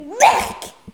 ajout des sons enregistrés à l'afk
beurk_04.wav